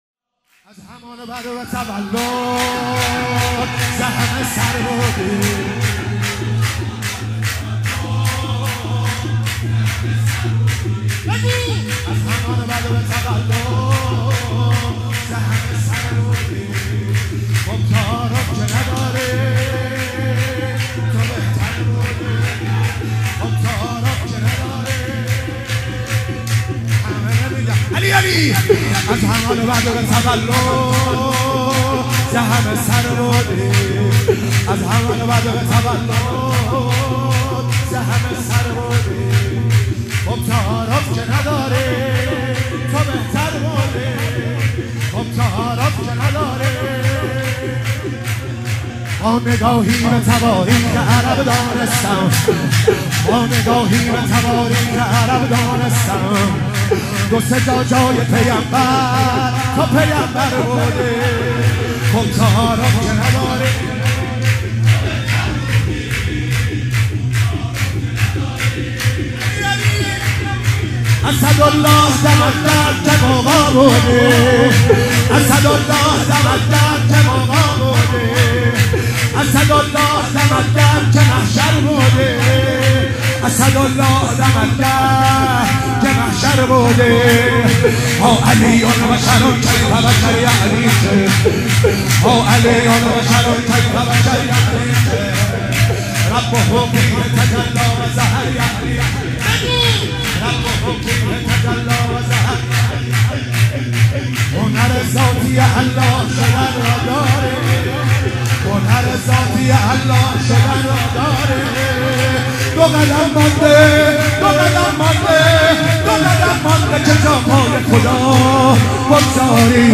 ولادت پیامبر (ص) و امام صادق (ع) 97 - شور - از همان بدو تولد
میلاد حضرت رسول اکرم (صلی الله علیه و آله) و امام صادق (علیه السلام)